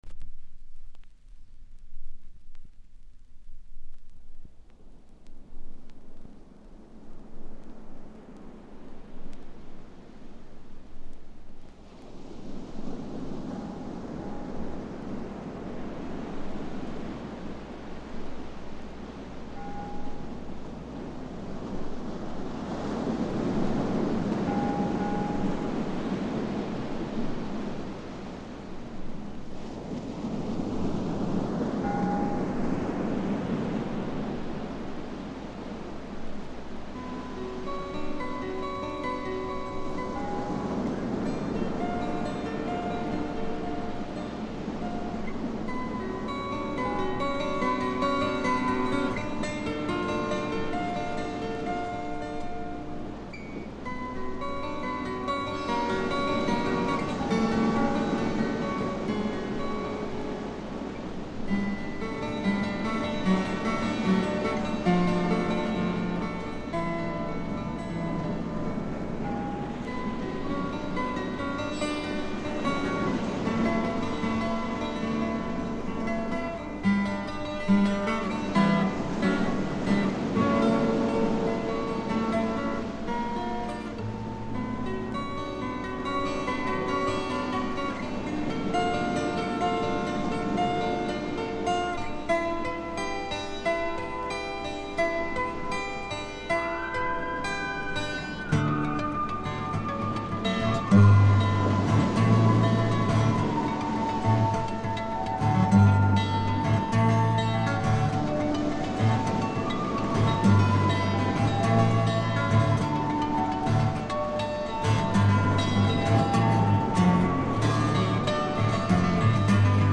ディスク：ざっと聴き流しました。ほとんどノイズ感無し。極めて良好に鑑賞できると思います。実際の音源を参考にしてください。
いうまでもなく米国産アシッド・フォークの超有名レア盤にして永遠の至宝です。
異常に美しいアコースティック・ギターの響きとスペイシーなシンセが共鳴する異空間。
試聴曲は現品からの取り込み音源です。